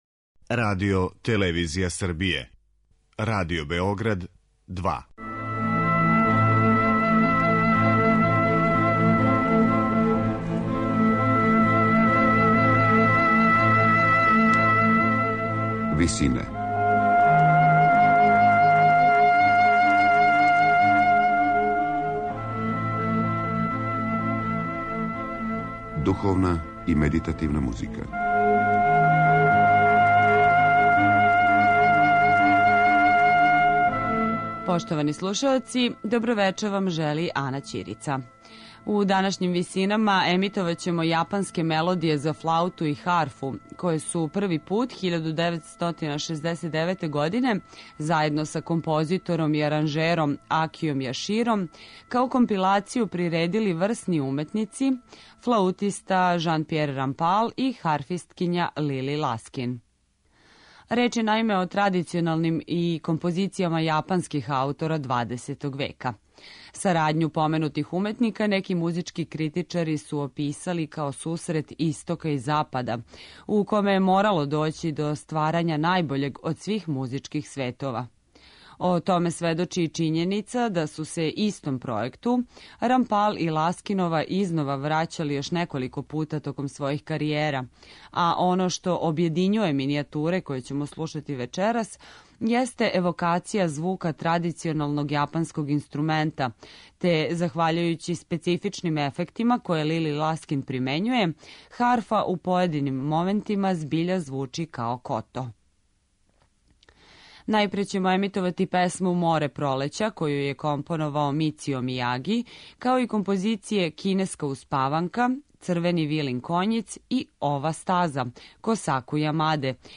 За флауту и харфу